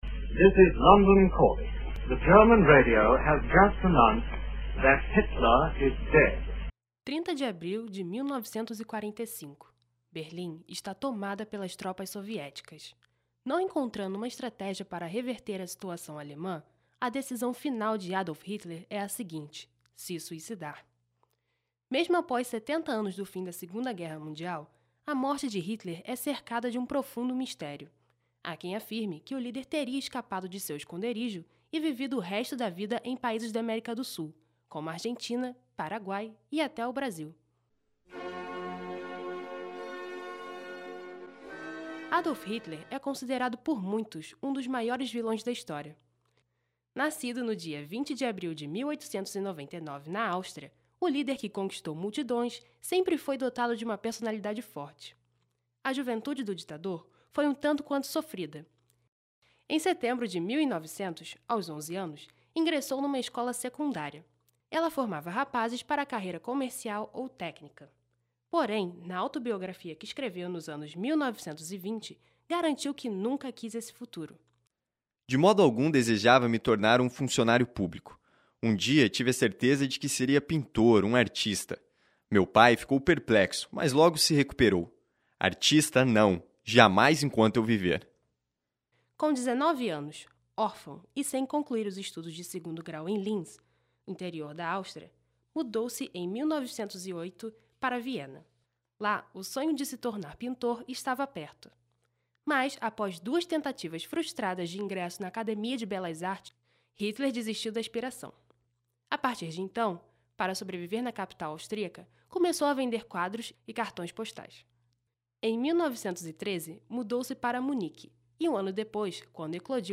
Anúncio da BBC da morte de Hitler
Mozart - Requiem
Anúncio do fim da Segunda Guerra Mundial na Hora do Brasil